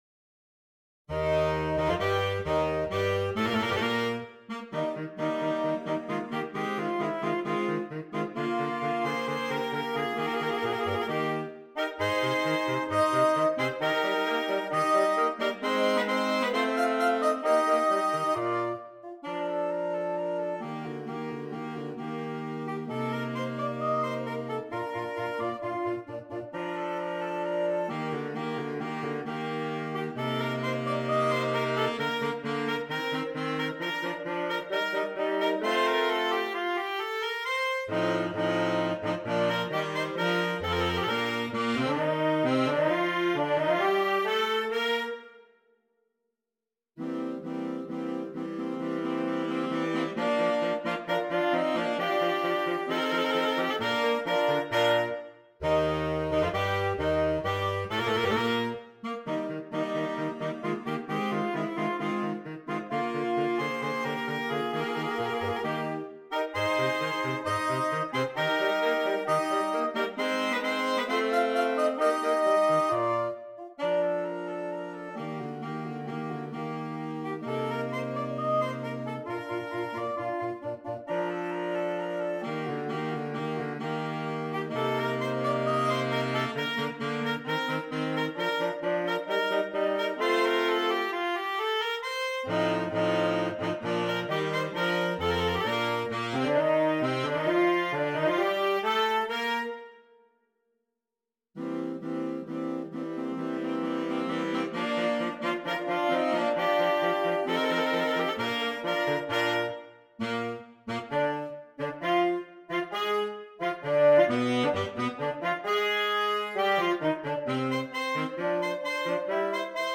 Voicing: Saxophone Quartet (AATB)